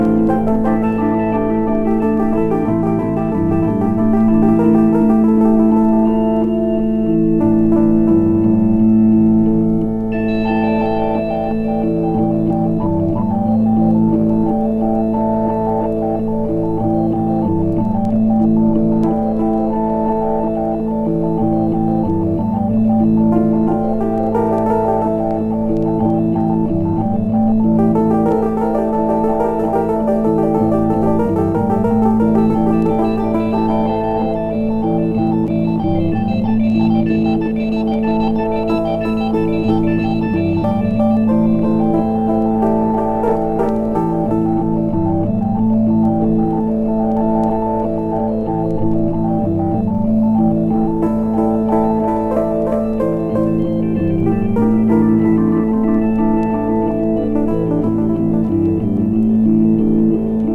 サウンドトラック盤